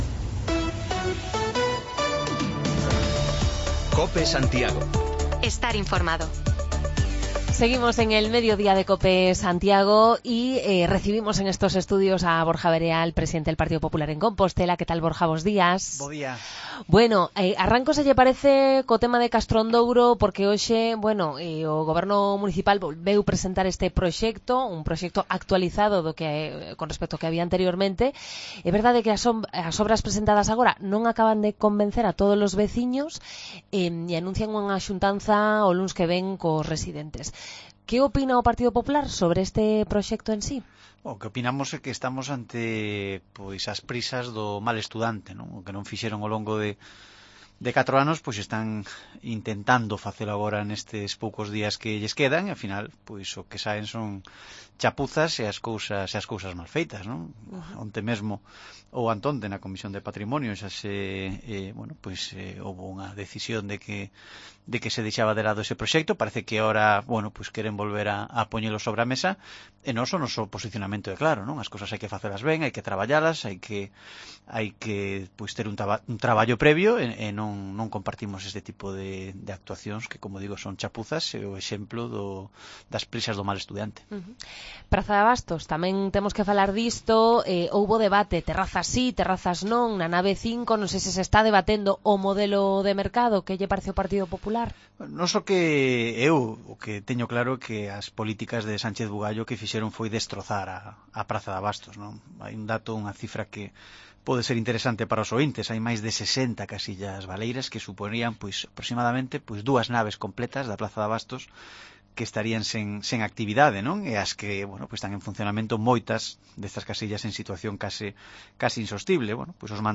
Llenamos el estudio de Cope Santiago hoy de visitas: primero el candidato del Pp a la alcadía